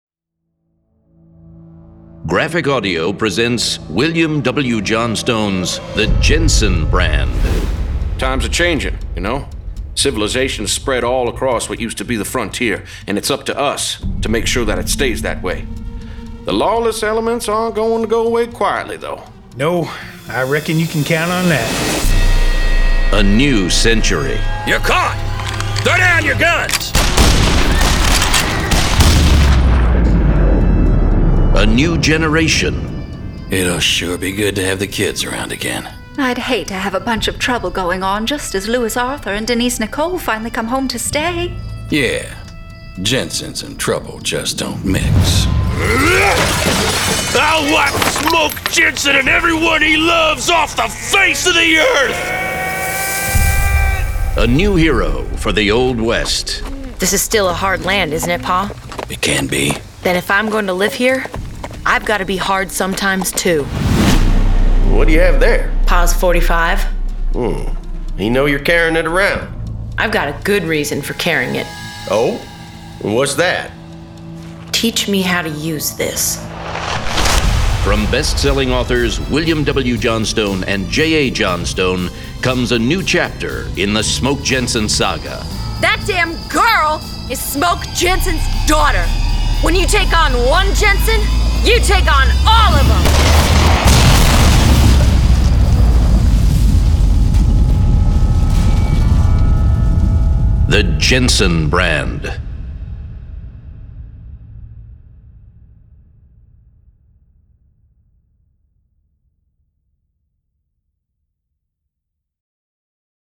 Full Cast. Cinematic Music. Sound Effects.
JENSENBRAND01-TRAILER.mp3